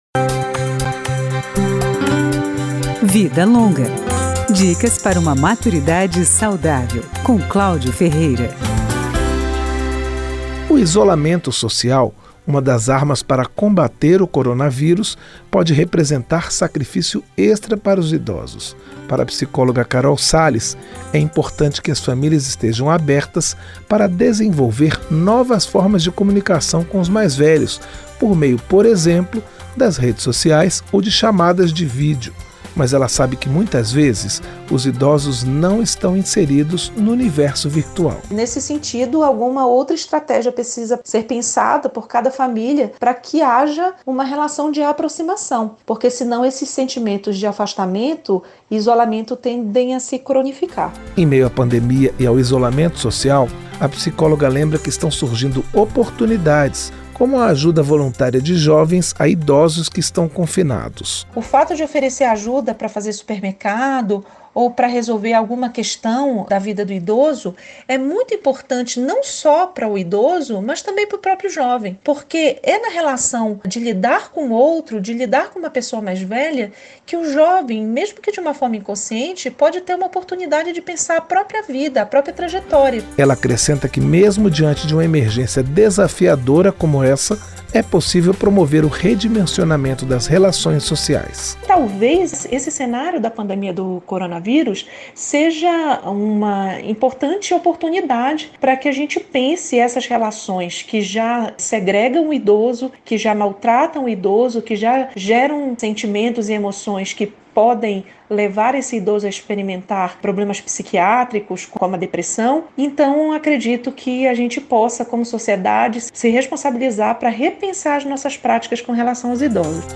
Programas da Rádio Câmara
Dicas sobre como envelhecer bem. Profissionais de várias áreas falam sobre alimentação, cuidados com a saúde, atividades físicas, consumo de drogas (álcool, cigarro) e outros temas, sempre direcionando seus conselhos para quem tem mais de 60 anos.